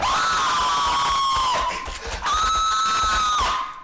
yelling-7